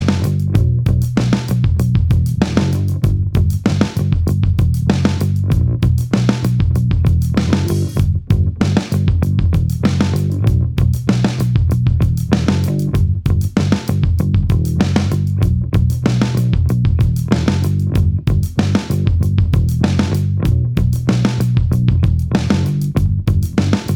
No Lead Guitar Rock 3:20 Buy £1.50